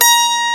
ZITHER2.WAV